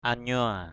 anyua.mp3